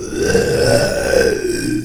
spawners_mobs_uruk_hai_neutral.1.ogg